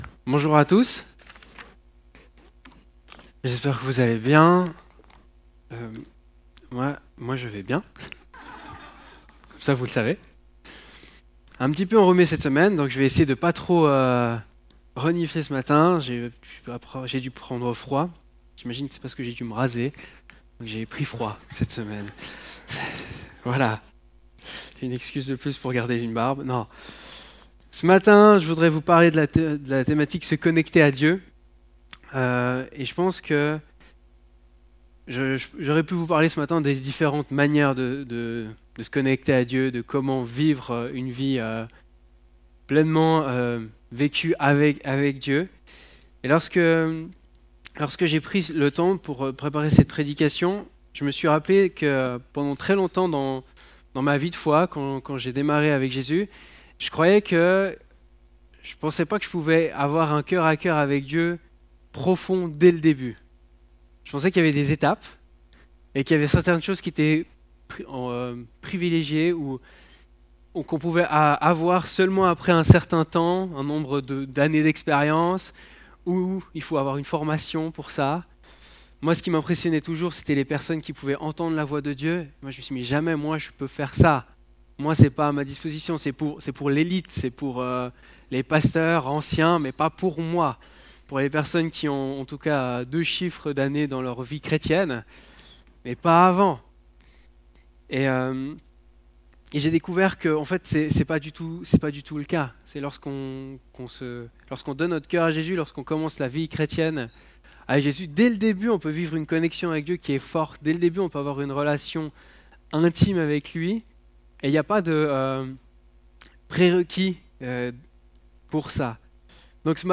Culte du 23 février 2020 « Se connecter à Dieu » 3/3